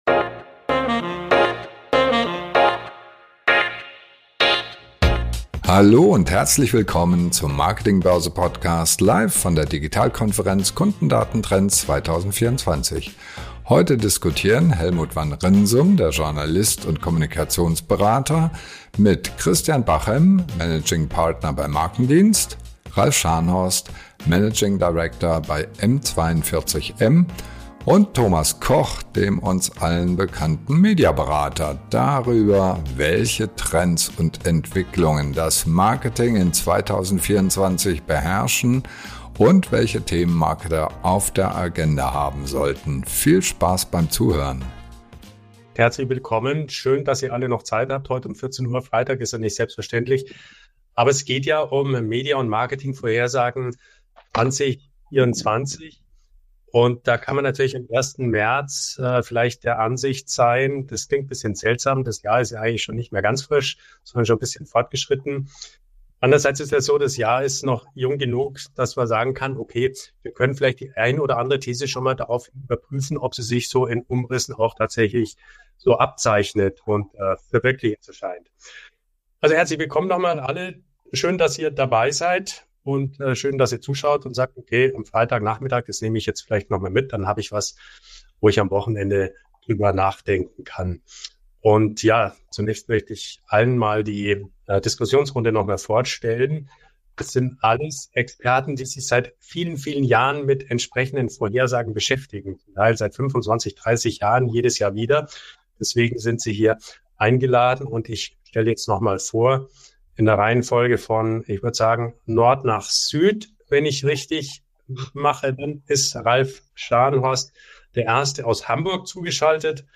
Roundtable